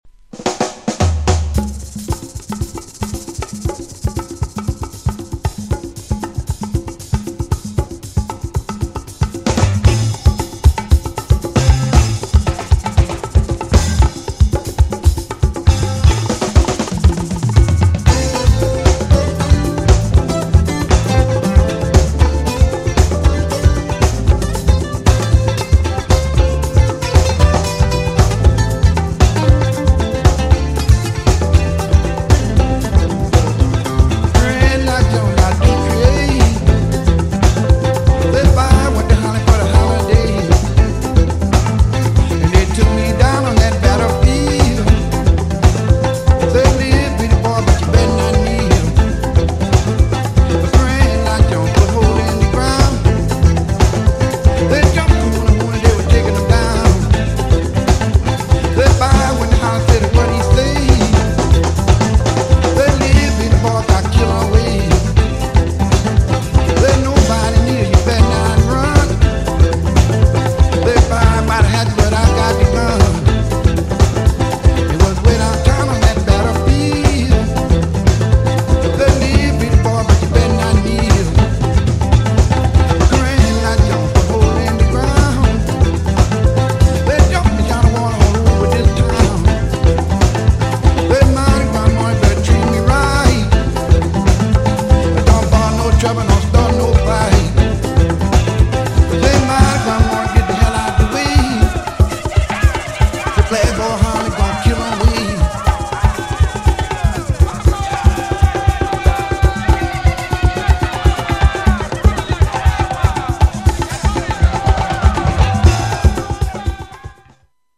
GENRE House
BPM 116〜120BPM